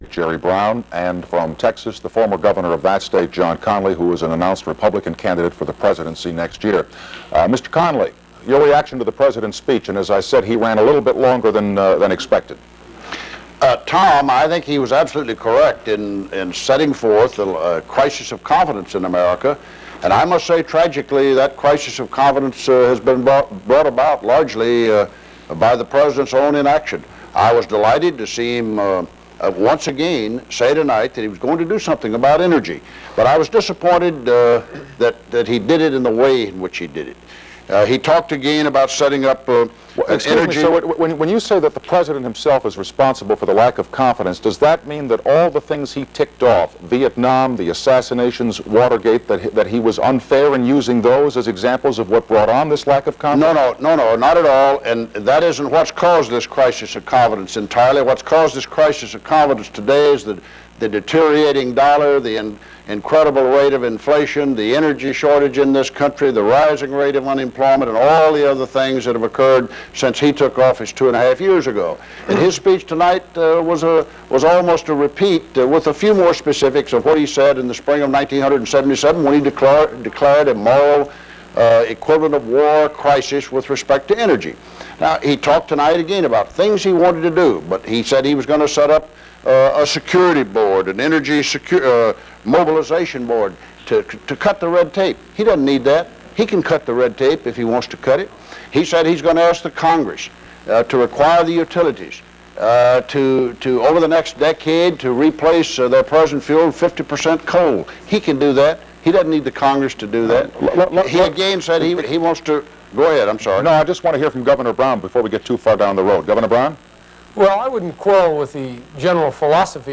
Brown and Connally also argue about the necessity for nuclear power. With the voice of reporter Tom Snyder.
Broadcast on NBC TV, July 15, 1979.